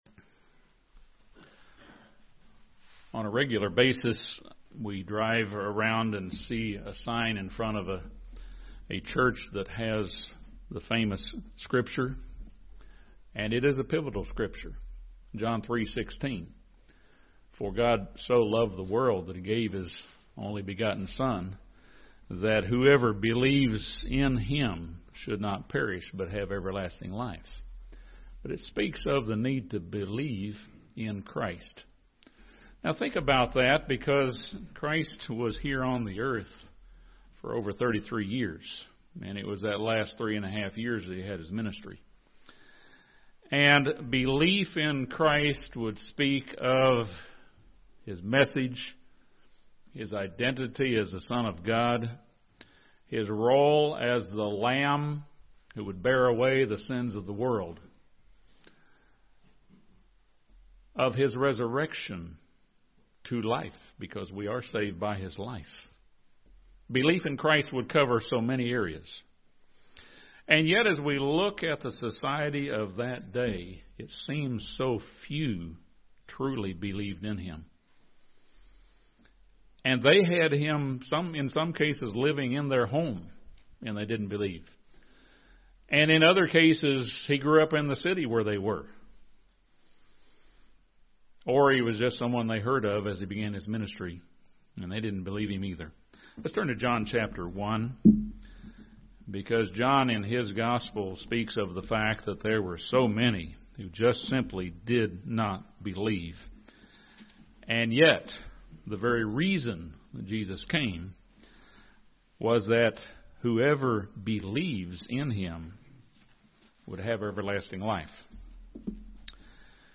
The gospels tell us that when Jesus walked the earth the Jews, those from His home town, and even His own brothers did not believe His message. This sermon discusses the possible role of familiarity, envy, and preconceived ideas as factors that can blind faith.